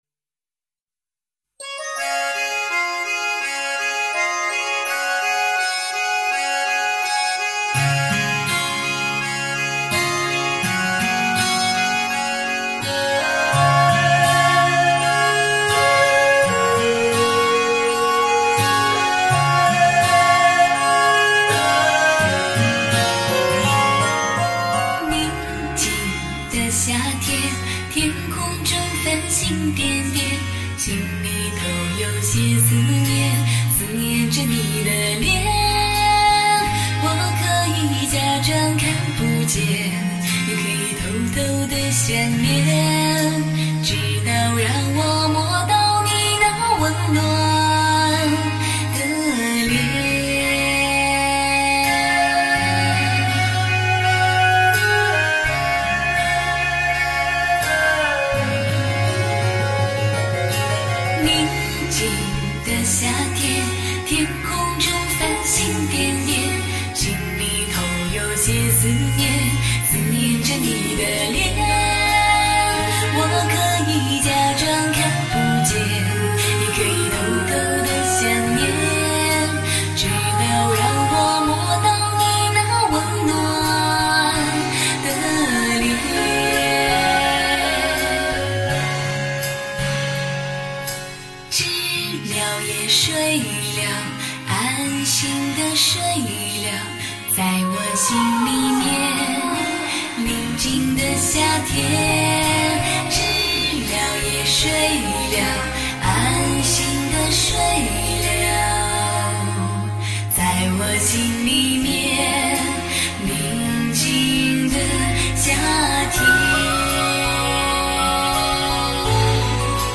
靓丽华美 纯真
清新亮丽，甘之如怡；娓娓唱来，深情甜美收放自如，高原天籁沁人心脾。